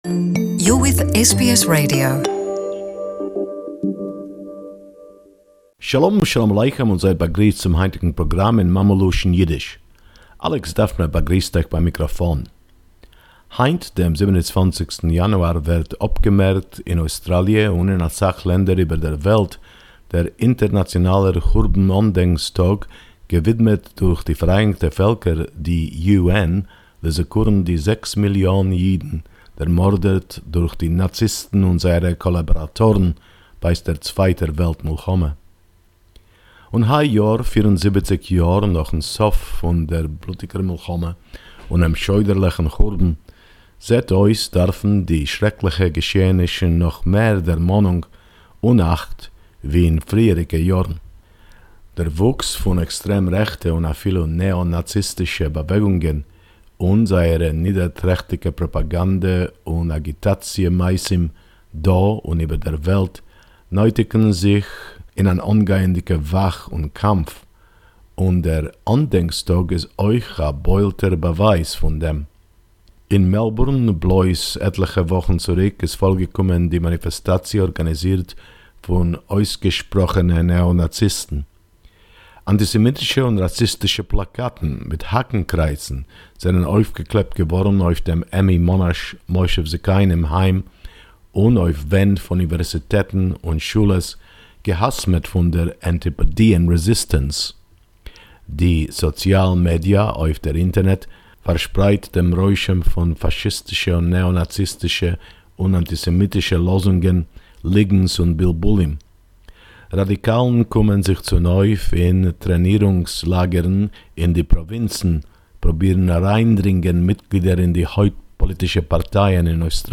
Yiddish report